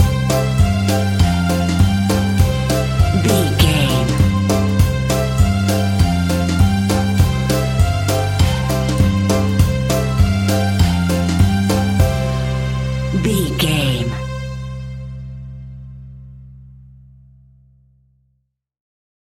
Ionian/Major
childrens music
instrumentals
childlike
cute
happy
kids piano